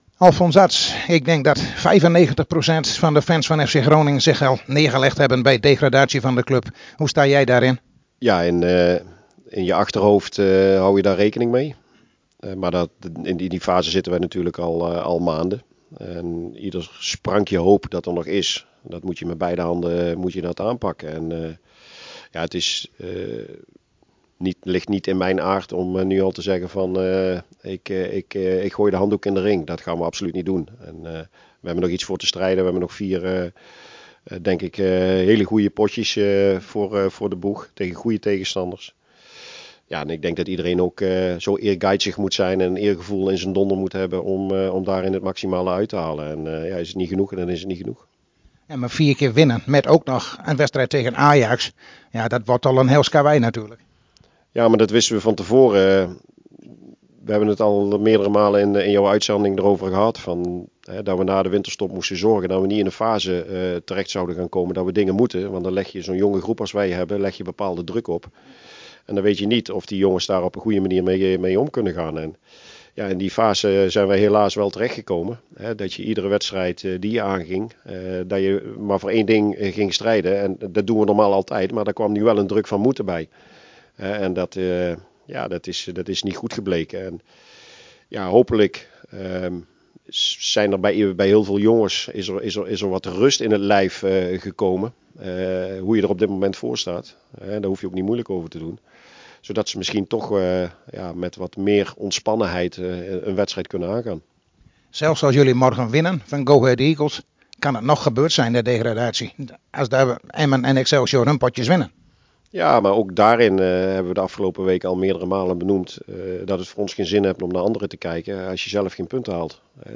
Hierbij het gesprek